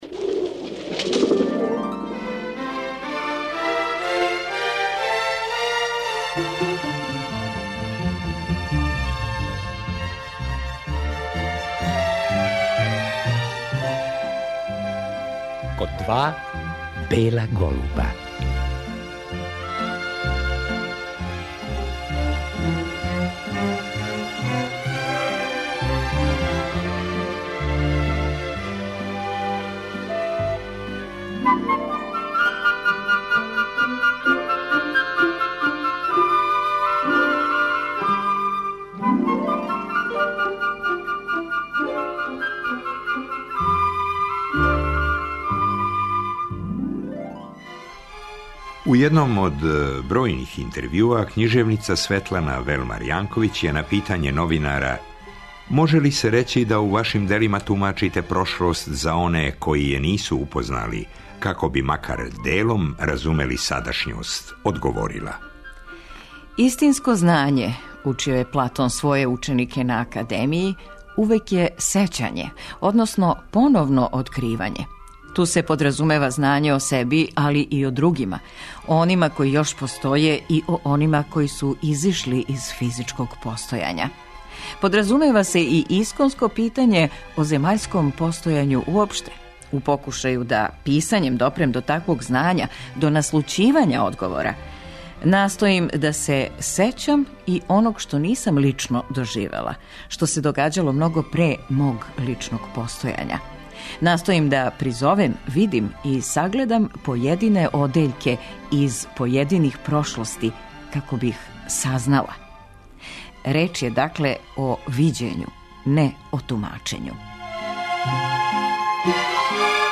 Најновија књига 'Капија Балкана - Брзи водич кроз прошлост Београда' Светлане Велмар Јанковић била је повод за разговор са књижевницом.